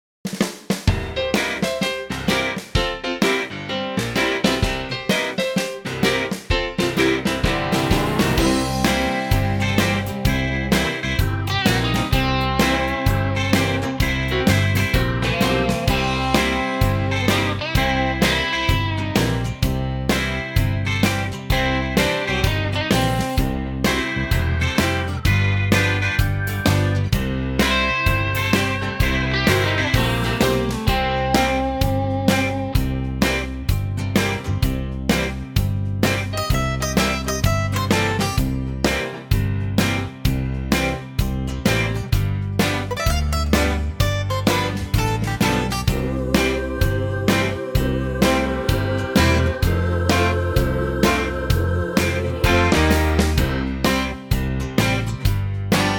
Unique Backing Tracks
8 bar intro and vocal in at 15 seconds
key - C to Db to D - vocal range - G to A